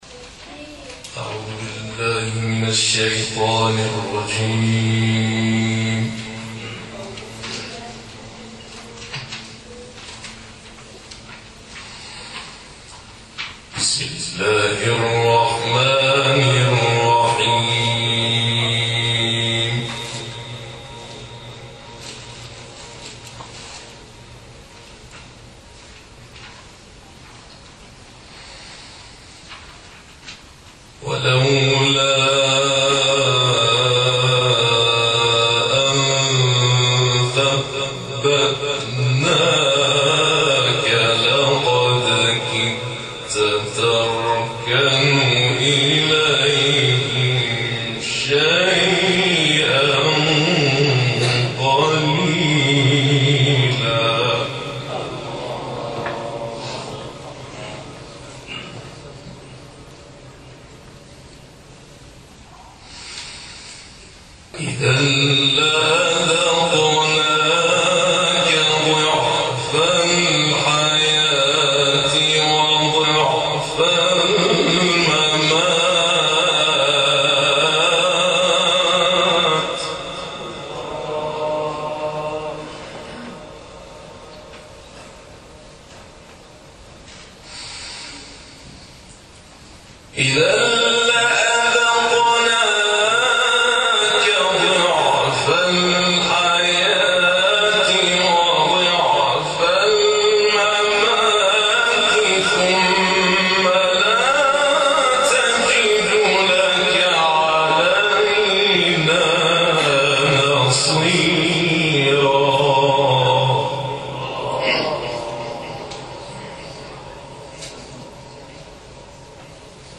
قرائت زیبای سوره اسراء